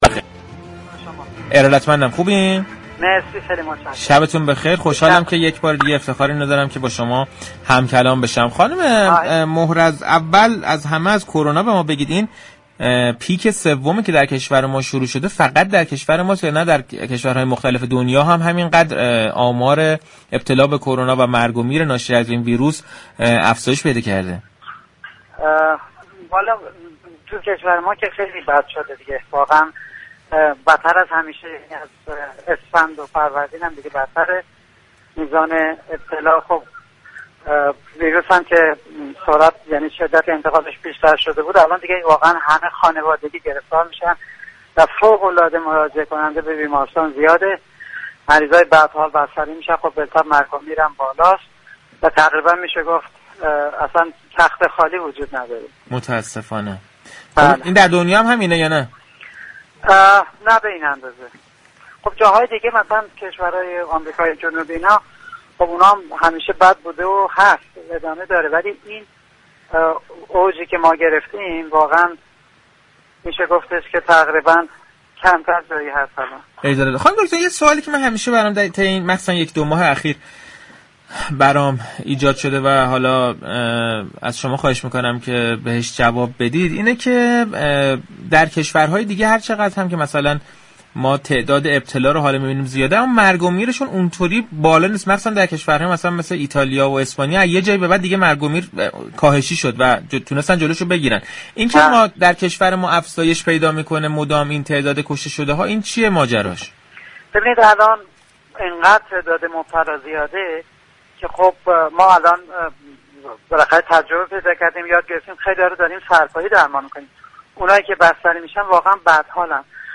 مینو محرز، متخصص بیماری های عفونی و عضو ستاد مبارزه با كرونا در گفتگو با رادیو تهران درباره كرونا تاكید كرد: سختگیری های بیشتر تنها راه جلوگیری از شیوع بیشتر بیماری كرونا است و لازم است كسانیكه ماسك نمی زنند جریمه شوند.